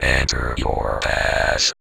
VVE1 Vocoder Phrases 16.wav